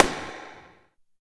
78 SNARE  -R.wav